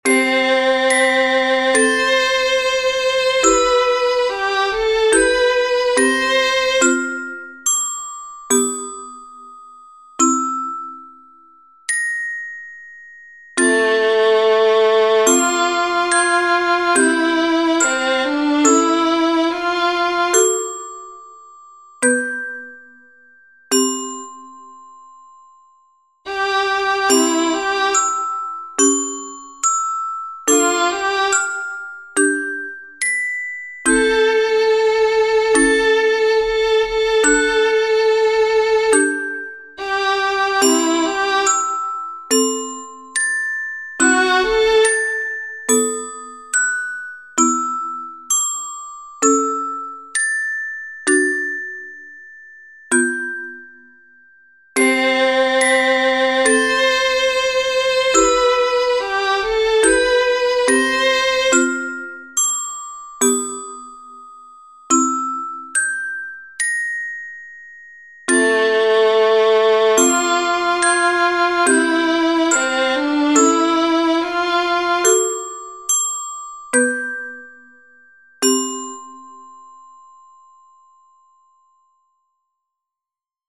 Here you have got the sound file with the silent bars.